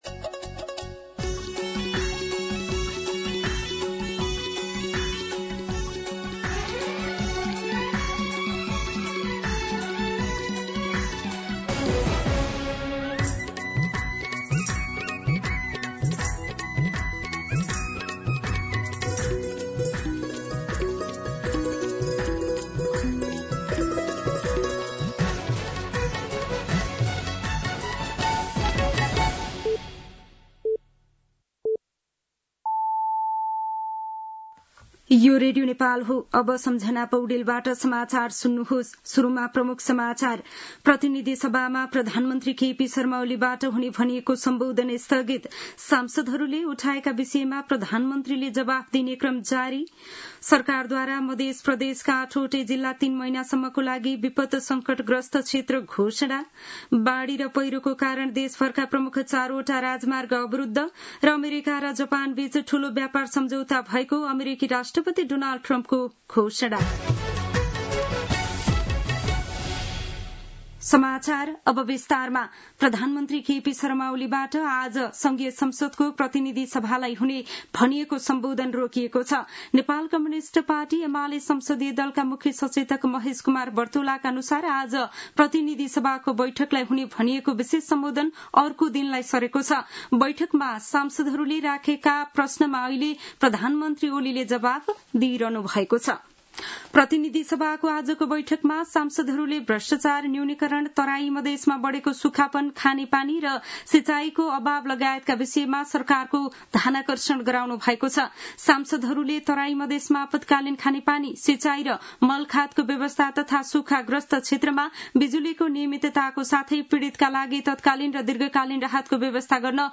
दिउँसो ३ बजेको नेपाली समाचार : ७ साउन , २०८२
3pm-News-04-7.mp3